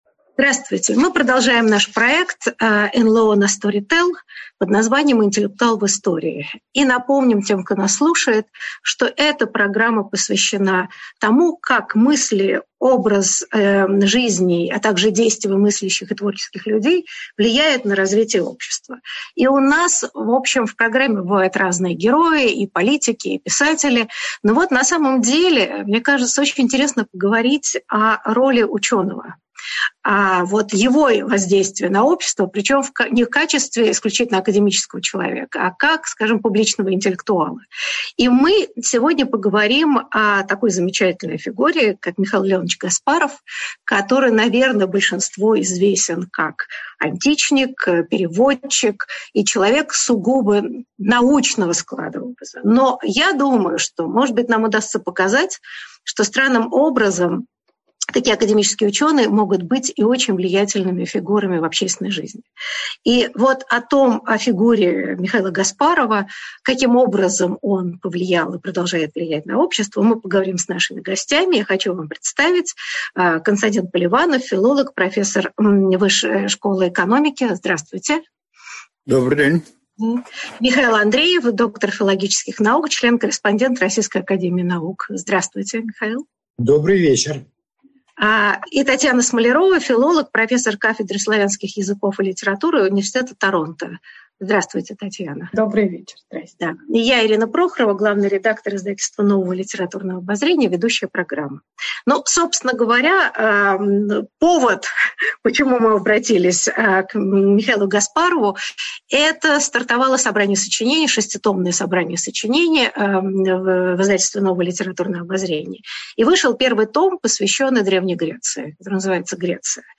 Аудиокнига Михаил Гаспаров | Библиотека аудиокниг